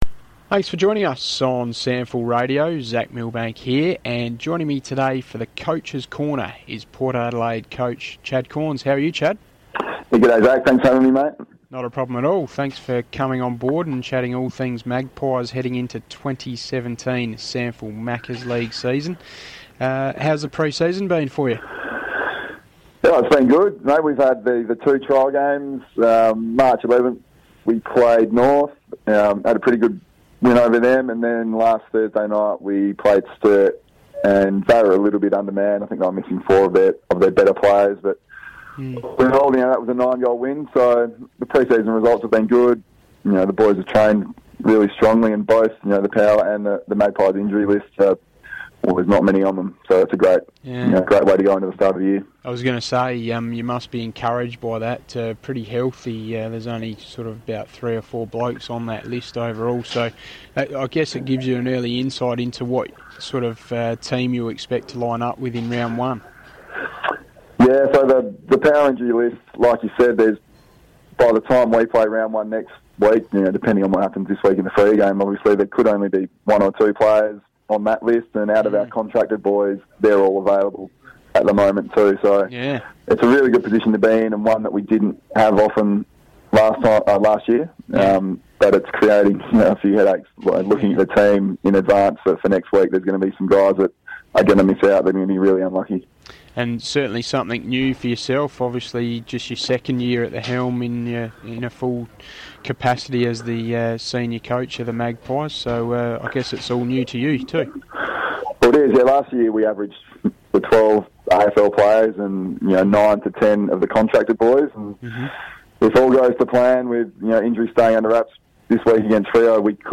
Chad Cornes talks to SANFL Radio